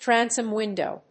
アクセントtránsom wìndow